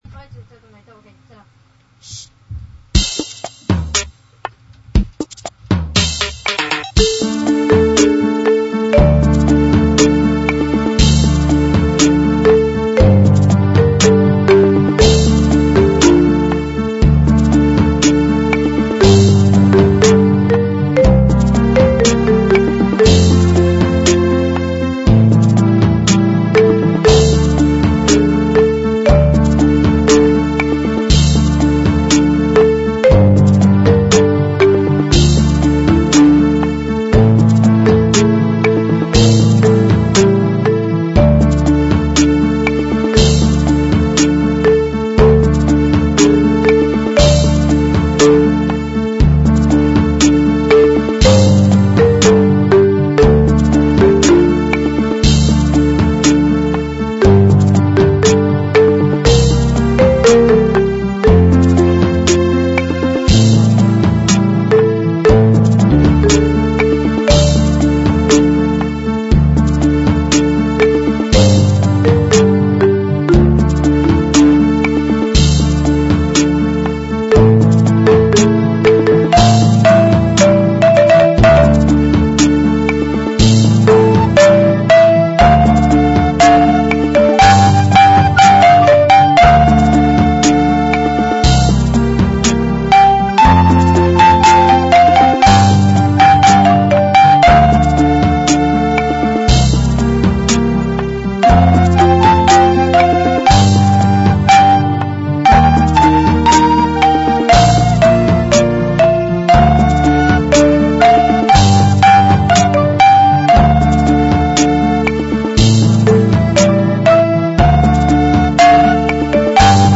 את זה הקלטנו אני ואחותי ממש מזמן.
נגינה נחמדה מאוד
החליל קצת זייף לפעמים אבל בסך הכללי נהנתי מאד